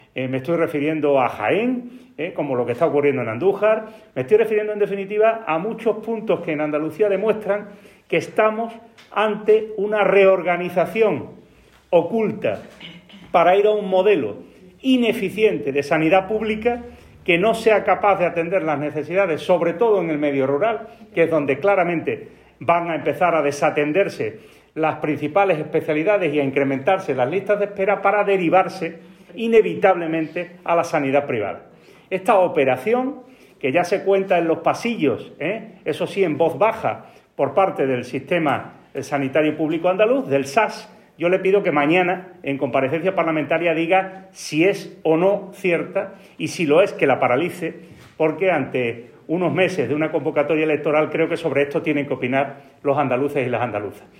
En rueda de prensa posterior, Espadas afirmó que Salud Responde “tiene que dejar de ser un servicio que se presta a través de un pliego administrativo que se contrata con una empresa privada y que al final responde a una filosofía de trabajo con la que nació, pero que claramente está ya superada por completo”.
Cortes de sonido